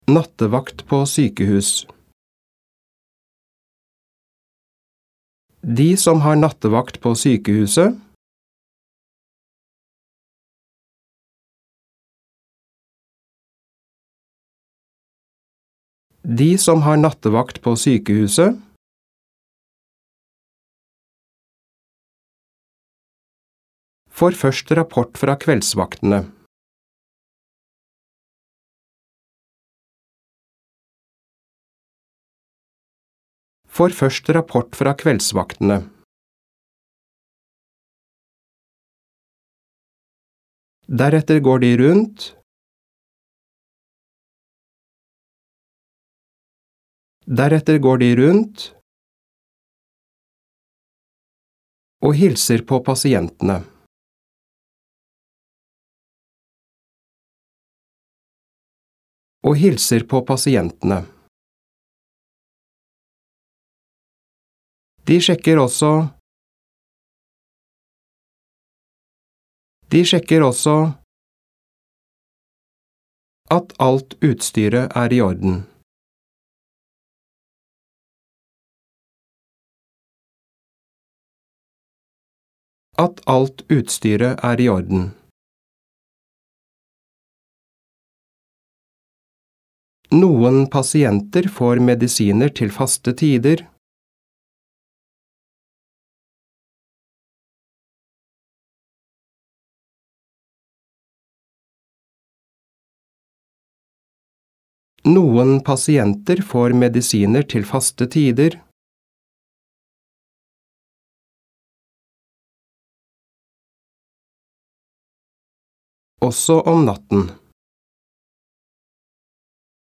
• Hver diktat leses i alt tre ganger.
• Andre gang leses hele setninger og deler av setninger.